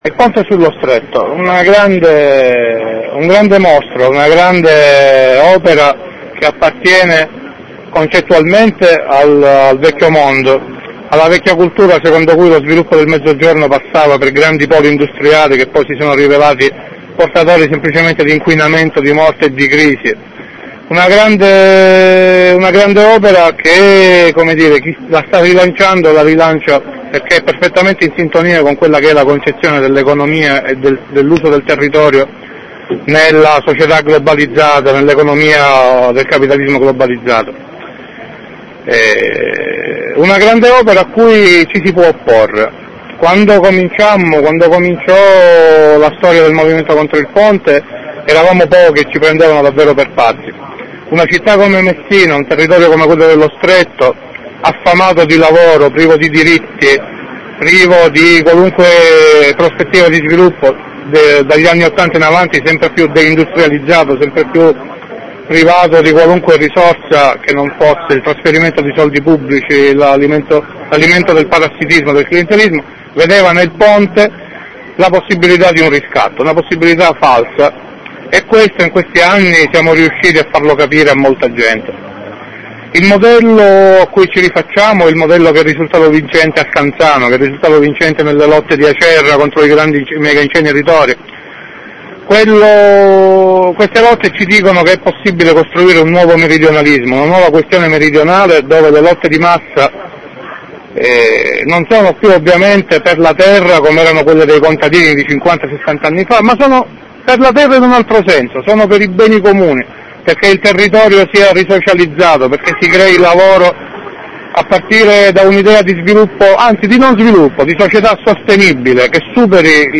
intervista con un fratello del FS di Messina.